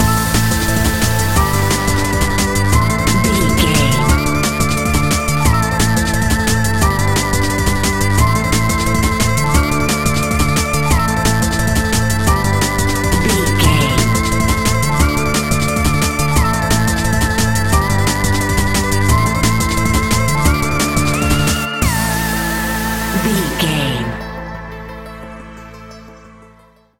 Aeolian/Minor
Fast
aggressive
dark
driving
energetic
groovy
drum machine
synthesiser
sub bass
synth leads